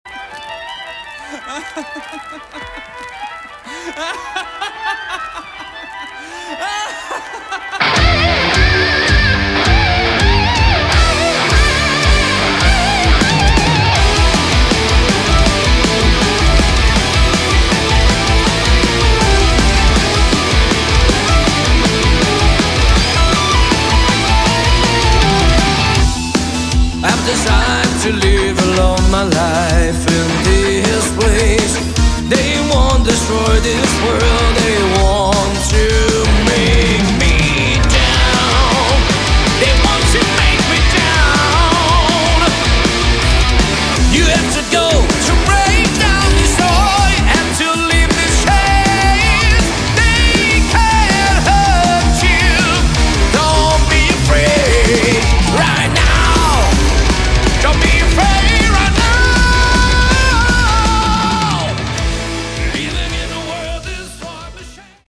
Category: Prog Rock
piano, keyboards
guitars
drums